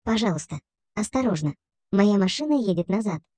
Звуки заднего хода
Задний ход грузовика голосовое оповещение на русском осторожно моя машина едет назад